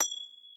snd_gem2.ogg